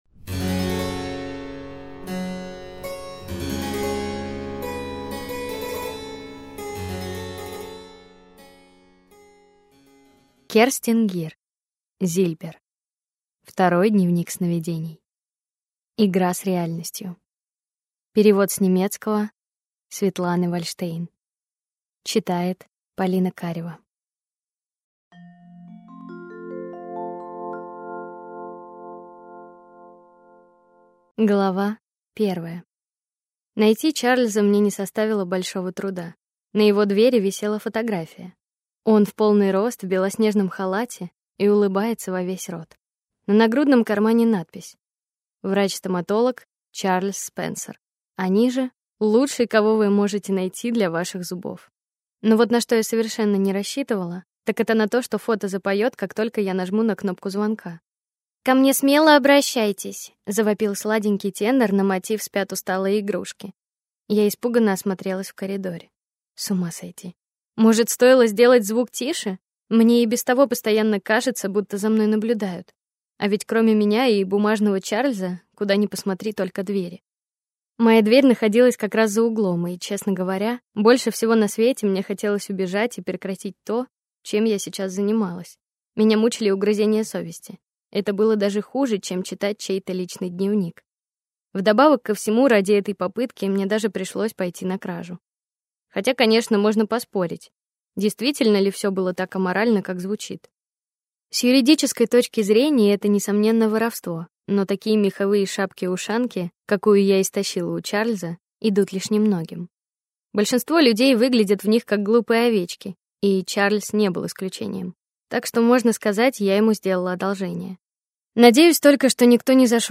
Аудиокнига Зильбер. Второй дневник сновидений | Библиотека аудиокниг